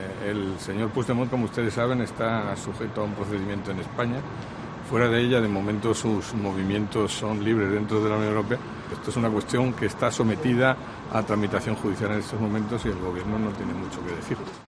"El señor Puigdemont está sujeto a un procedimiento judicial en España, fuera de ella, de momento, sus movimientos son libres dentro de la Unión Europea", afirmó Dastis a su llegada al Consejo de ministros de Exteriores, que se celebra este lunes en Bruselas.